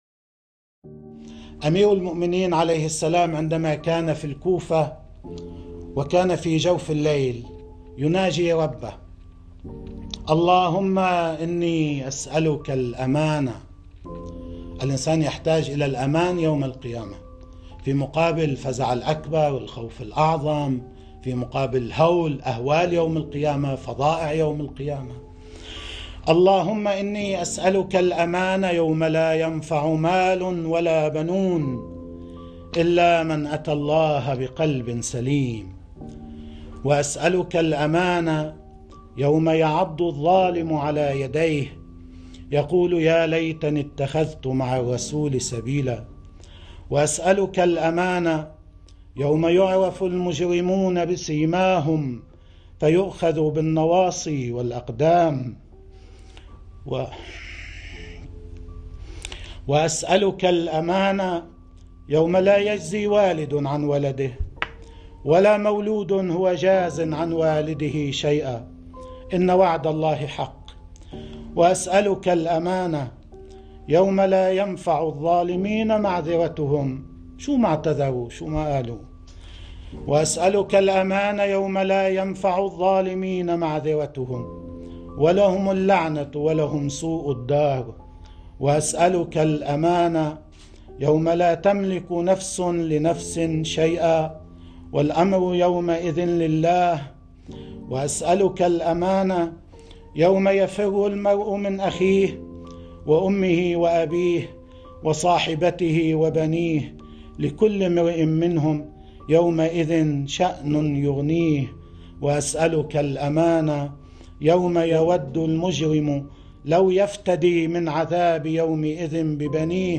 مقطع صوتي وفيديو ل "كلام سماحة السيد حسن نصر الله "من مناجاة امير المؤمنين الإمام علي عليه السلام في مسجد الكوفة